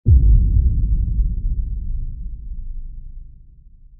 Big Explosion
Big Explosion is a free sfx sound effect available for download in MP3 format.
# explosion # boom # impact About this sound Big Explosion is a free sfx sound effect available for download in MP3 format.
013_big_explosion.mp3